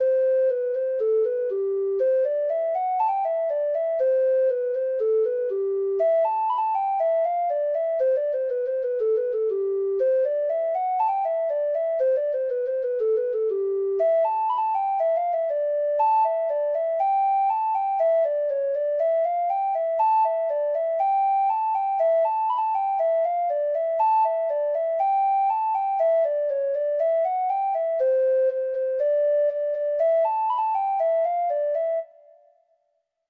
Free Sheet music for Treble Clef Instrument
Reels
Irish